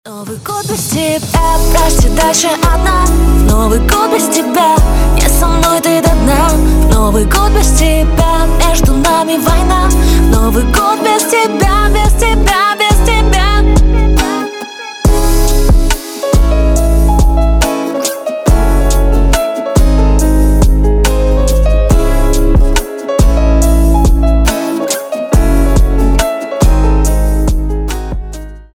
• Качество: 320, Stereo
грустные
пианино